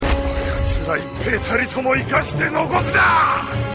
So for the DVD, they got Takashi Ukaji (his voice actor) back in to re-record either all or almost all of his dialogue. The DVD version of Raoh sounds a lot angrier and louder than before.